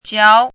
chinese-voice - 汉字语音库
jiao2.mp3